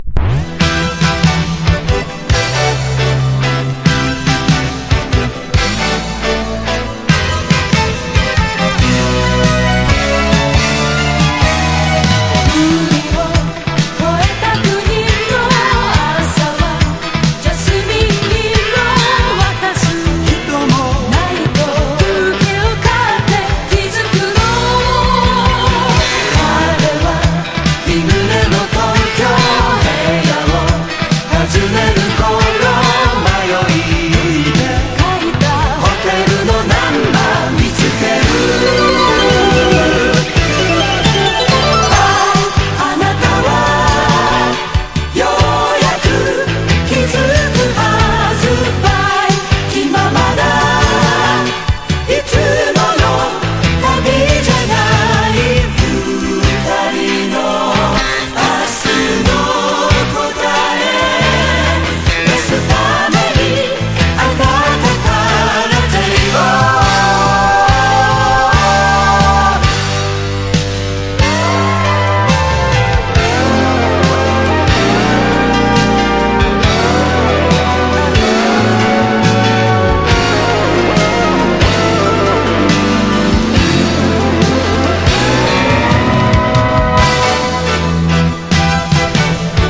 形式 : 7inch / 型番 : 07 5H 92 / 原産国 : JPN
シティポップ・ディスコ
60-80’S ROCK
所によりノイズありますが、リスニング用としては問題く、中古盤として標準的なコンディション。